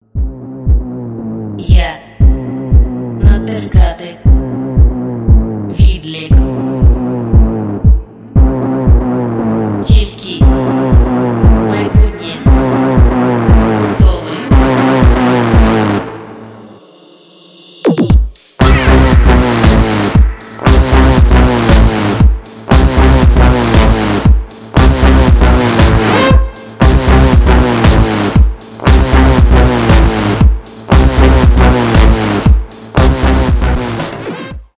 folktronica , электронные